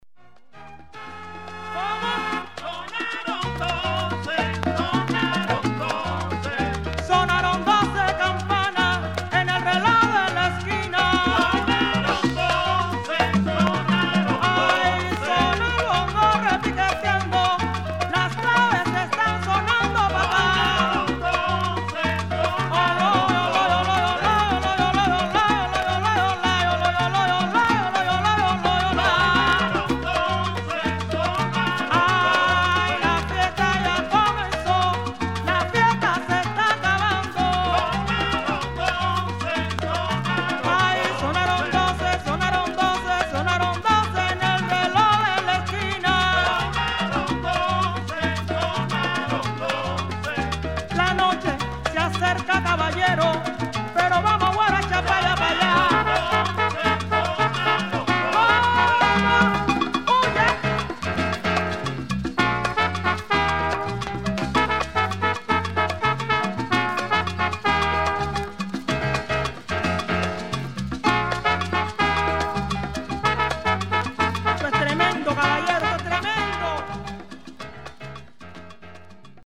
女性ボーカル
SALSA/GUARACHA
CONDITION 見た目VG(+),音はVG+/VG+ (リングウェアー , 経年劣化) (VINYL/JACKET)
1980年代初頭の録音と思われる。
パンチの効いた切れのある黒いボーカルが”サルサ”な演奏にぴったりはまる。